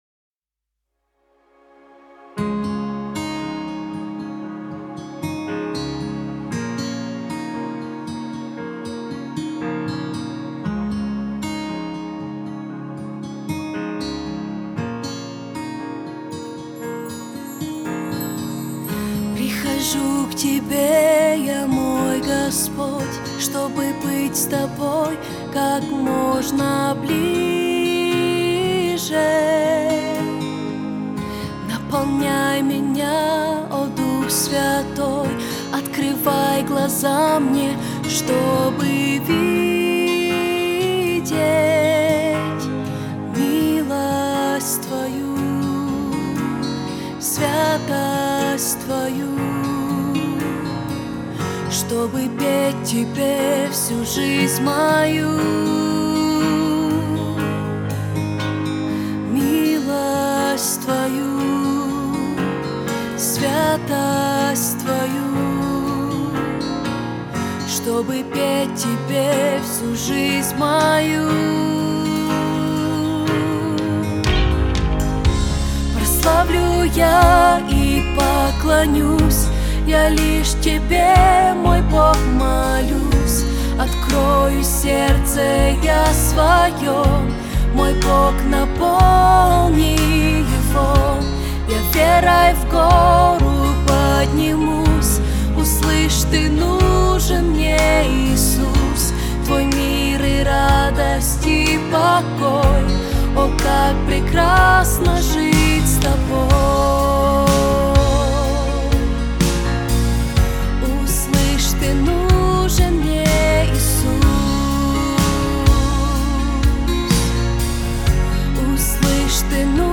песня
32245 просмотров 9471 прослушиваний 3898 скачиваний BPM: 124